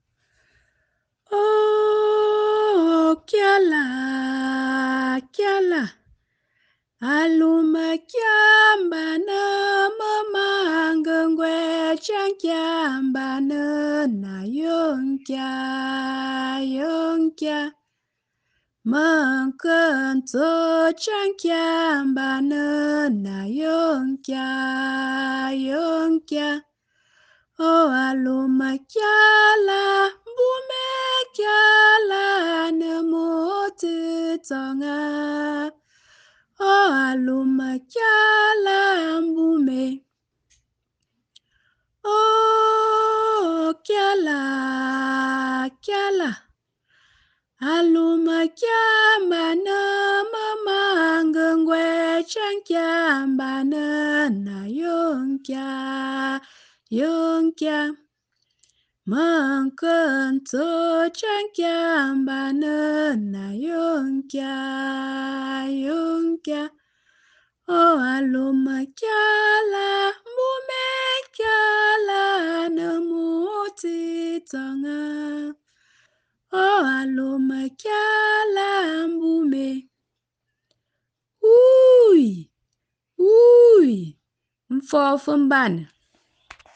“MBÀNƏ̀” is the Traditional Anthem of the Nkwen People. It is a typical song of the people with origin from our forefathers. It is a symbol of peace, progress, and unity.